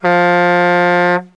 Сигнал Большого Грузовика